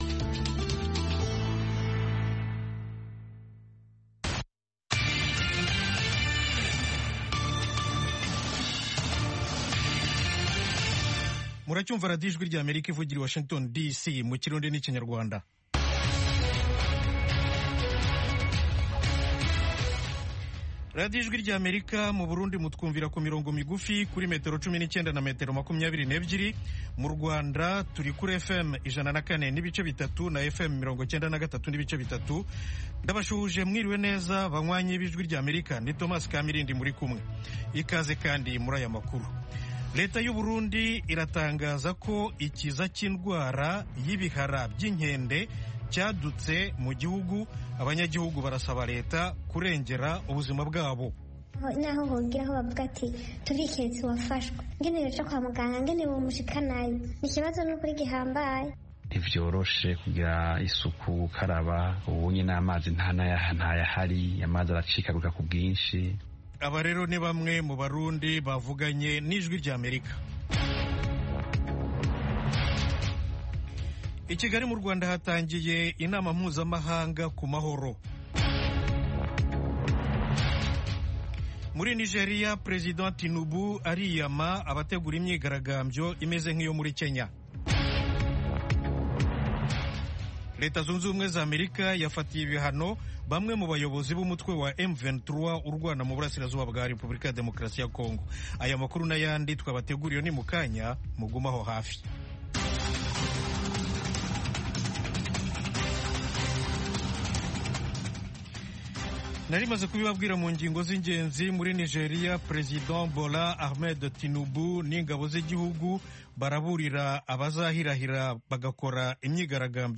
Ejo ni ikiganiro cy'iminota 30 gitegurwa n'urubyiruko rwo mu Rwanda, kibanda ku bibazo binyuranye ruhura na byo. Ibyo birimo kwihangira imirimo, guteza imbere umuco wo kuganiro mu cyubahiro, no gushimangira ubumwe n'ubwiyunge mu karere k'ibiyaga bigari by'Afurika.